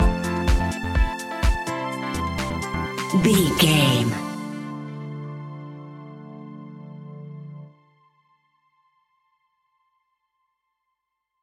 Aeolian/Minor
D
groovy
energetic
uplifting
hypnotic
drum machine
synthesiser
strings
funky house
deep house
nu disco
upbeat
funky guitar
synth bass